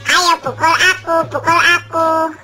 Efek suara Ayo Pukul Aku Pukul Aku
Kategori: Suara viral
Keterangan: Sound effect meme 'Ayo Pukul Aku' cocok buat edit video lucu! Frasa ini sering dipakai saat bercanda atau memprovokasi dengan nada menantang dan lucu, terutama untuk meredakan situasi tegang.
efek-suara-ayo-pukul-aku-pukul-aku-id-www_tiengdong_com.mp3